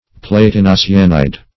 Search Result for " platinocyanide" : The Collaborative International Dictionary of English v.0.48: Platinocyanide \Plat`i*no*cy"a*nide\, n. (Chem.) A double cyanide of platinum and some other metal or radical; a salt of platinocyanic acid.
platinocyanide.mp3